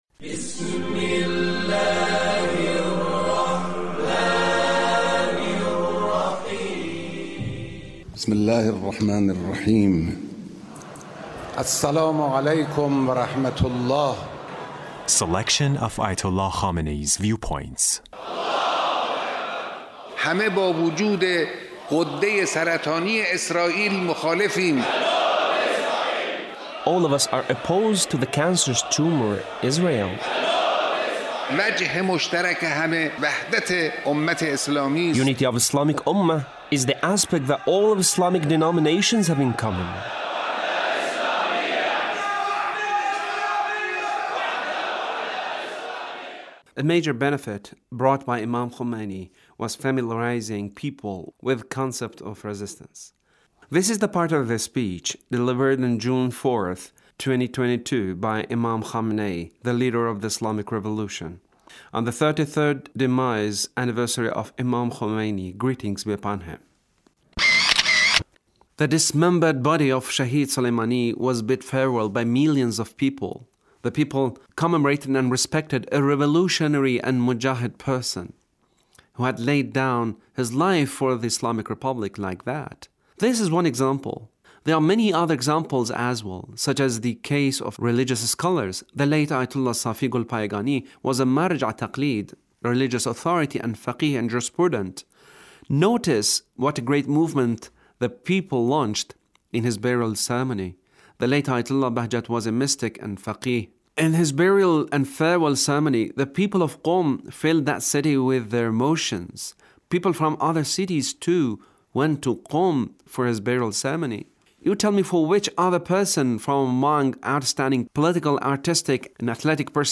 The Leader's speech on The Demise Anniversary of The Imam